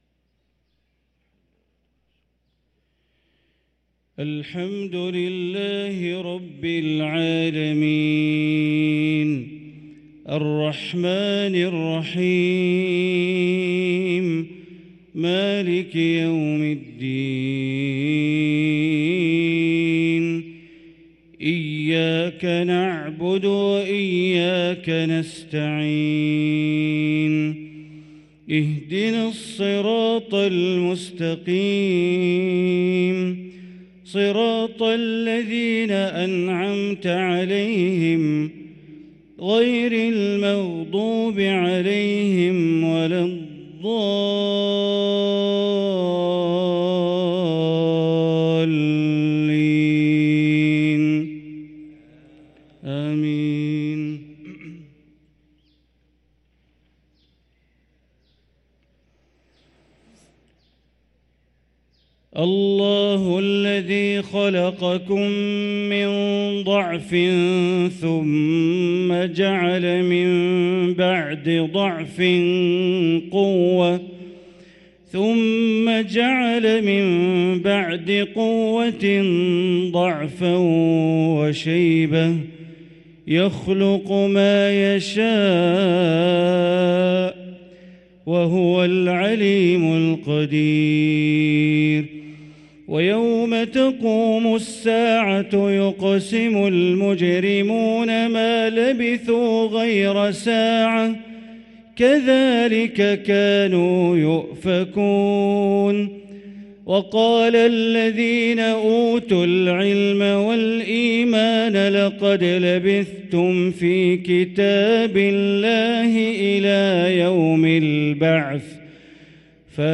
صلاة العشاء للقارئ بندر بليلة 13 شعبان 1444 هـ